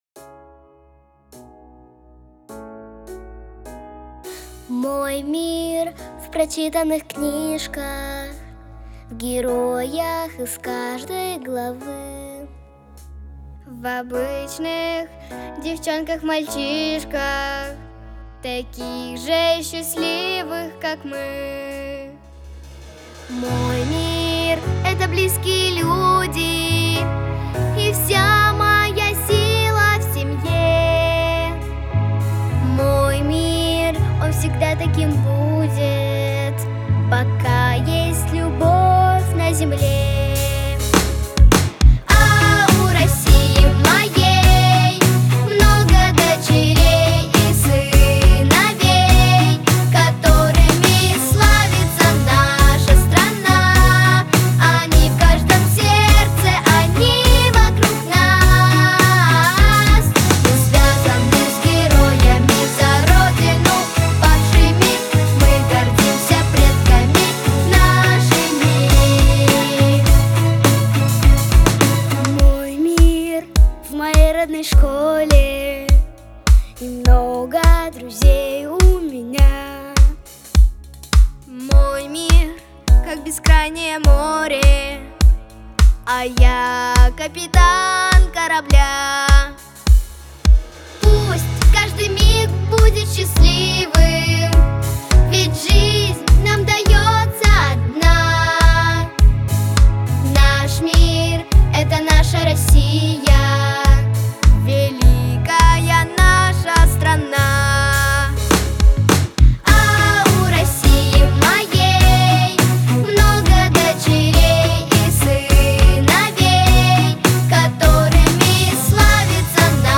• Качество: Хорошее
• Жанр: Детские песни